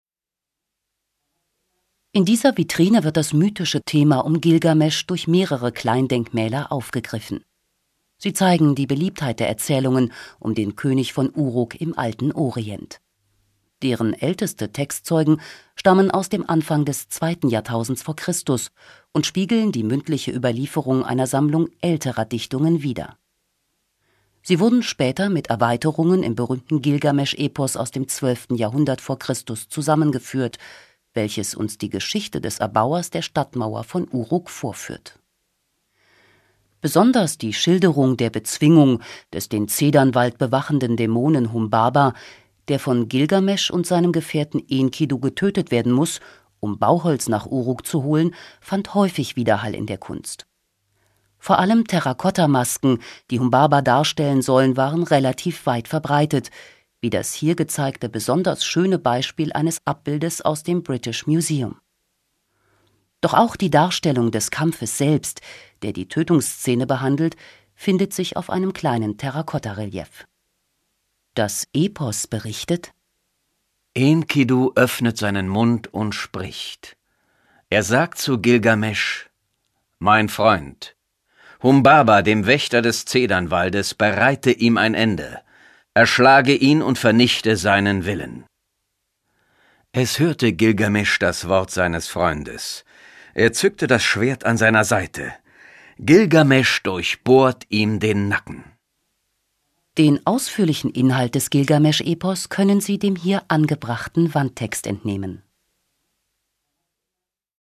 Animations and Podcasts
These audioguides are packed with insightful background information on the Epic of Gilgamesh and the ancient megacity Uruk.